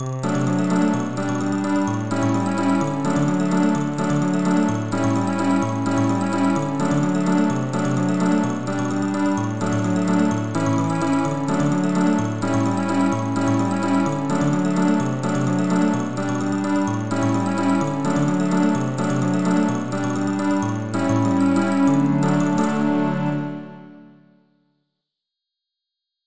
MPEG ADTS, layer III, v2, 128 kbps, 16 kHz, Monaural
自動で作曲し、伴奏つきの
合成音声で歌います。